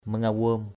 /mə-ŋa-wo:m/ mangawom mZ_w’ [Cam M] (d.) gia đình = famille = family.